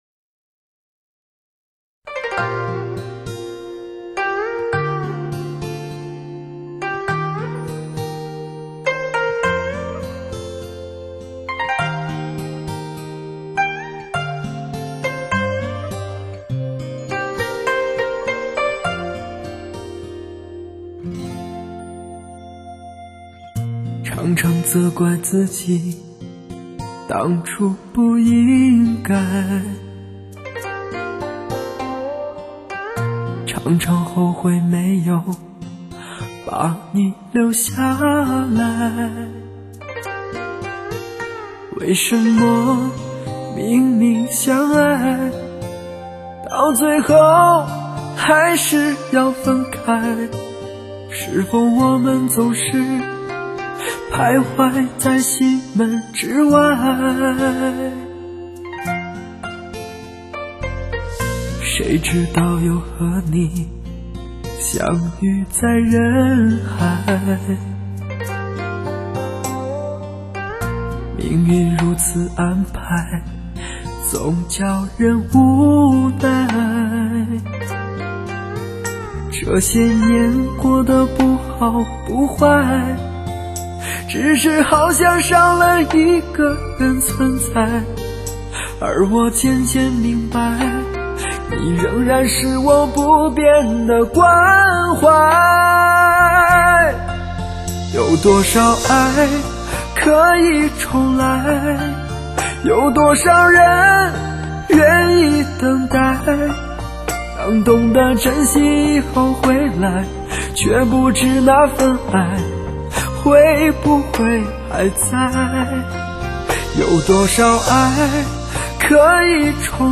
男声发烧碟少有的佳作之一。收录众多男声演绎的精品，经典老歌。
既保持了原作品风格的编配，也加入不少新颖发烧元素，令你听出耳油。